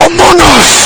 Guy Yelling Among Us
guy-yelling-among-us.mp3